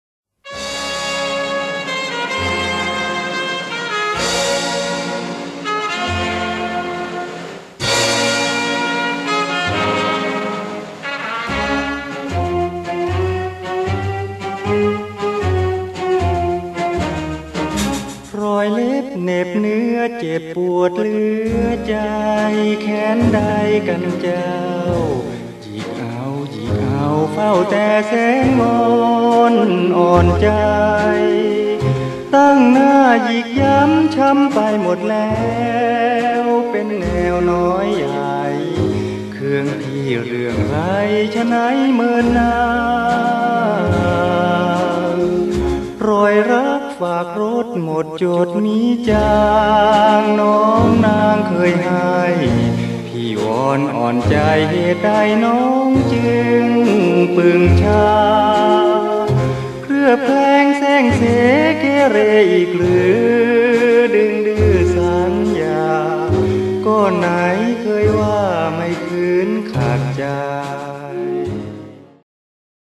USB MP3 เพลงต้นฉบับเดิม